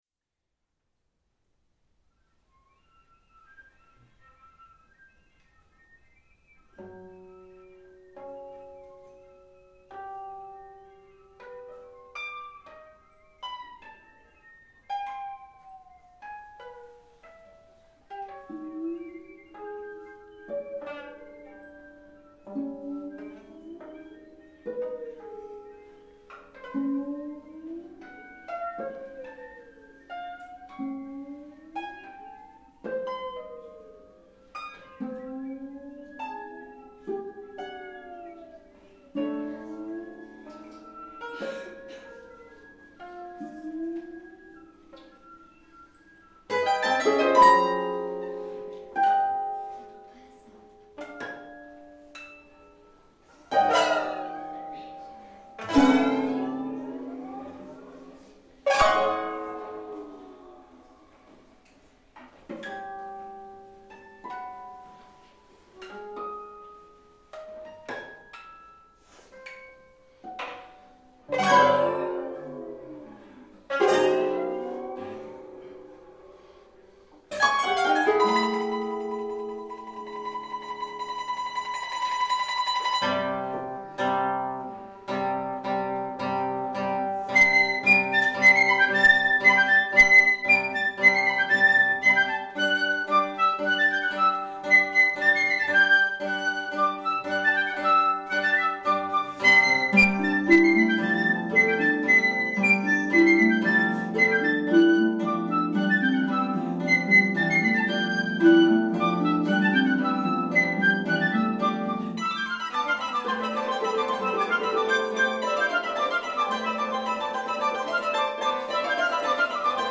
opéra de chambre
voix de femme, flûte, mandoline, guitare et harpe
6 musiciens :
mezzo-soprano
récitant